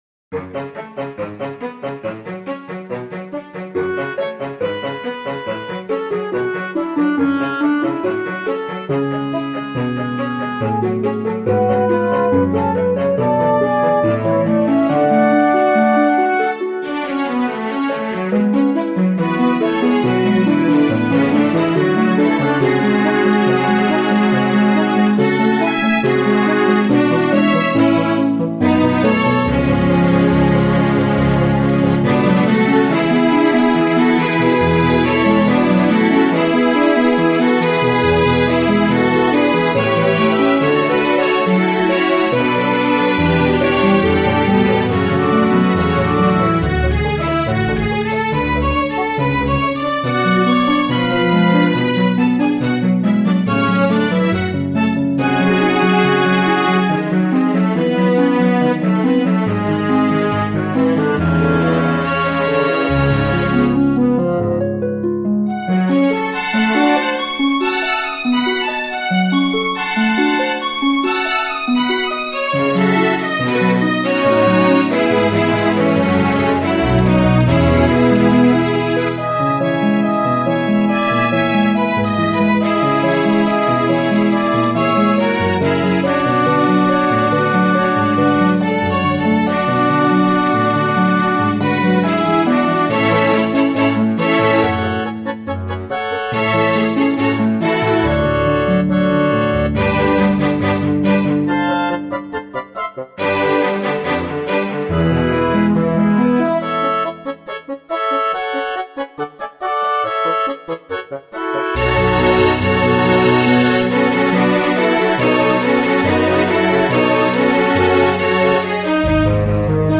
Suite Arabesque - IV Passapiede de Claude Debussy, œuvre impressionniste pour piano avec arrangement pour orchestre
Suite_IV_orchestre.mp3